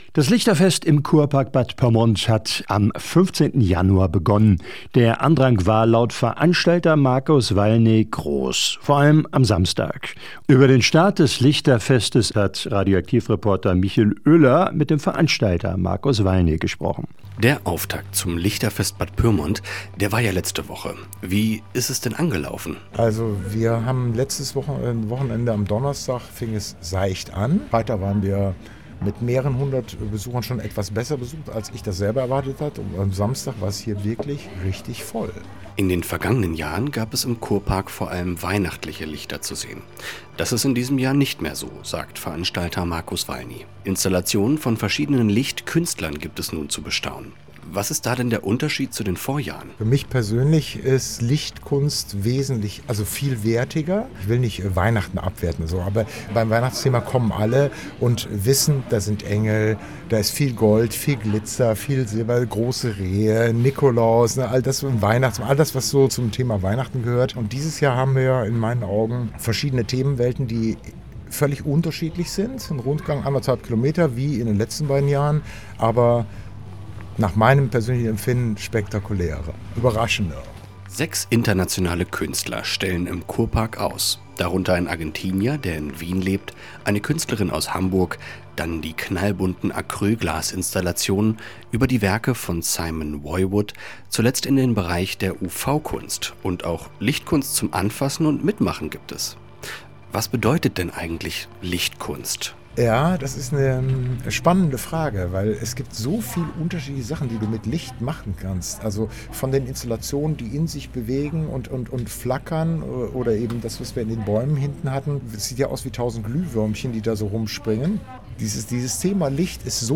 Bad Pyrmont: Das Lichterfest im Kurpark hat begonnen und kann bis zum 28. Februar besucht werden. Unsere Reporter berichten.